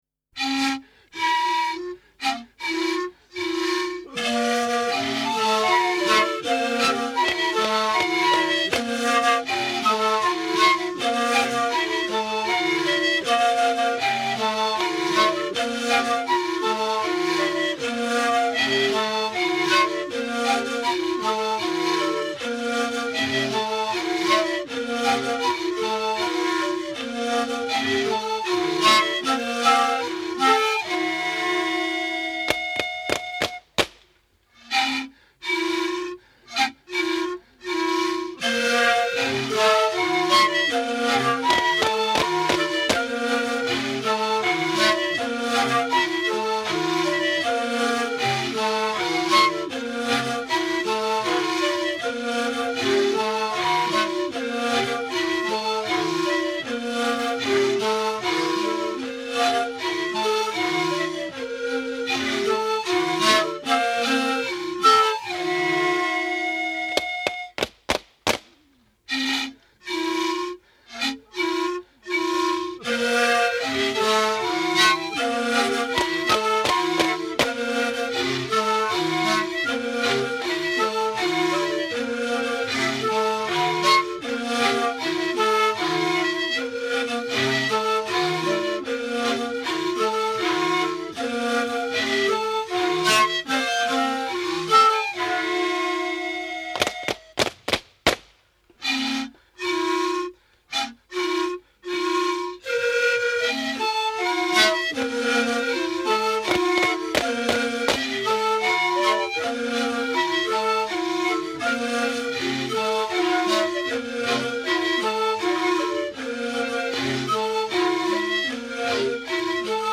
Ensemble de 10 flûtes de
Pour cet ensemble de dix musiciens, certains étant assis et d’autres debout, les compositions sont polyphoniques à quatre parties doublées seulement une fois à l’octave.
Dix femmes dansent autour des flûtistes en rapportant une partie de percussions.